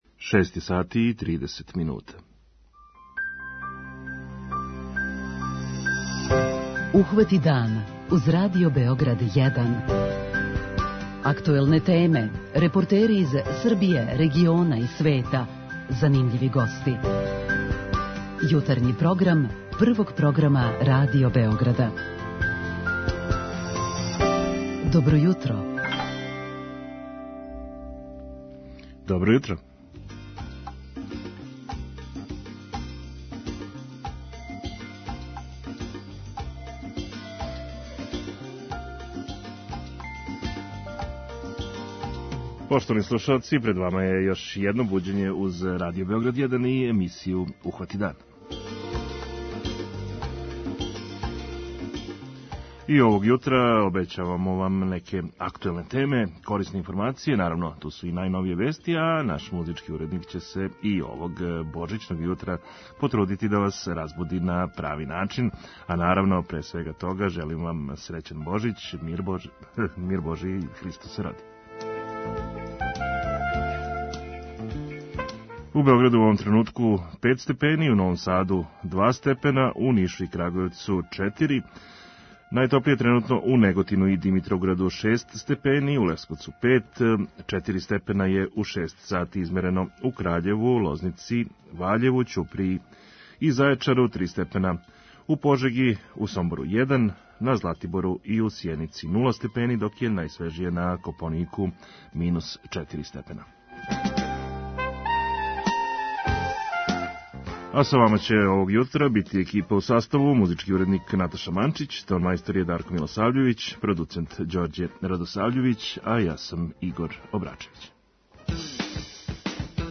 37.78 MB Ухвати дан Autor: Група аутора Јутарњи програм Радио Београда 1!
О томе разговарамо са нашом познатом поп певачицом, Иваном Јордан.